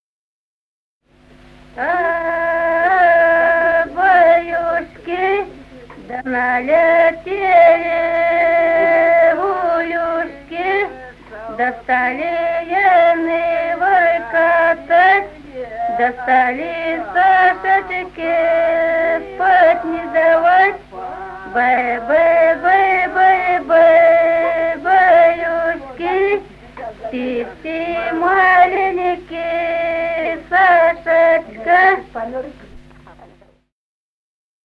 Песни села Остроглядово. А-а, баюшки.